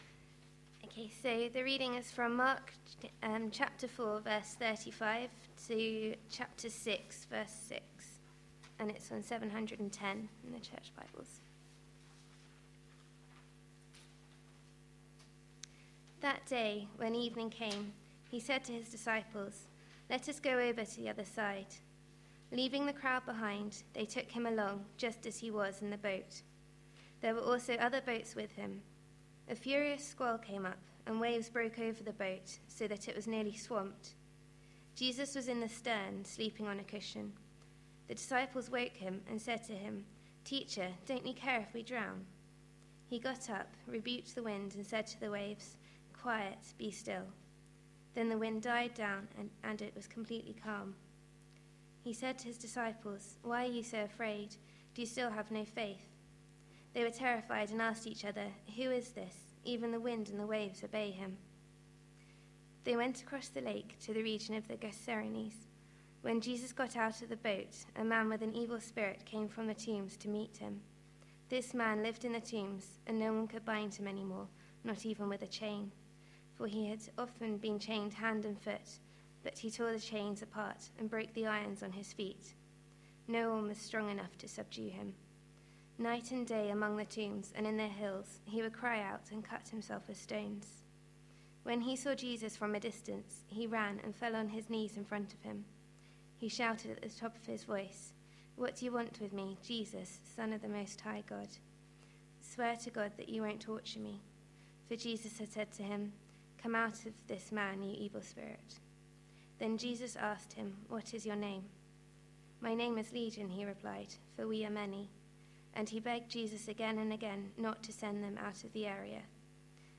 A sermon preached on 6th November, 2011, as part of our Mark series.